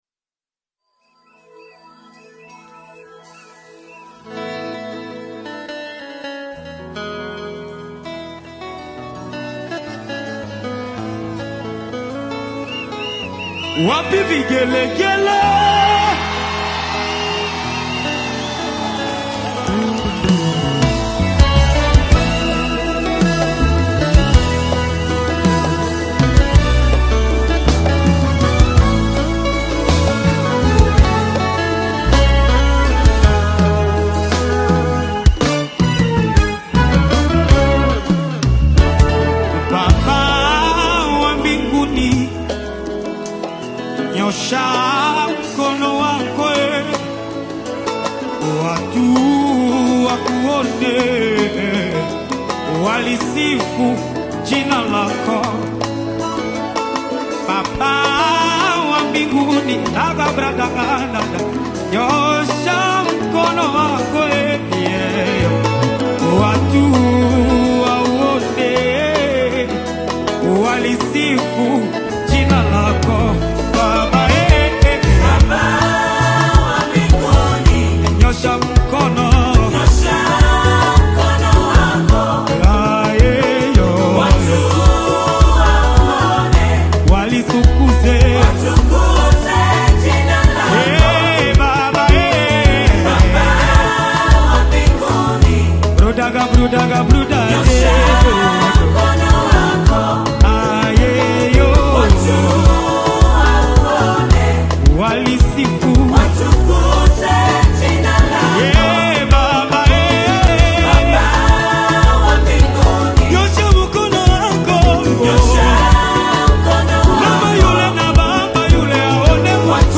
Nyimbo za Dini Worship music
Worship Gospel music track